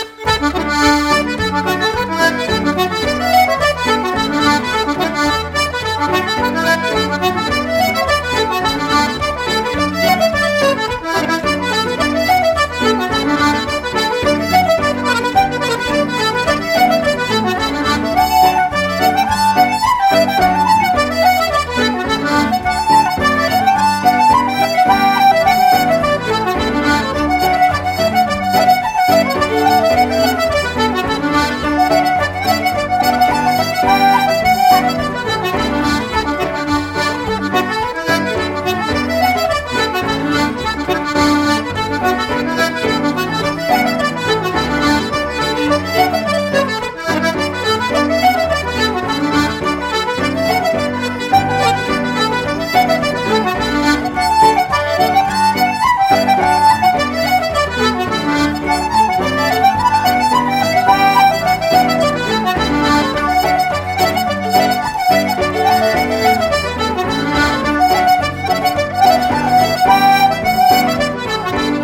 Players set to learn for this week Miss Monahan reel set 1.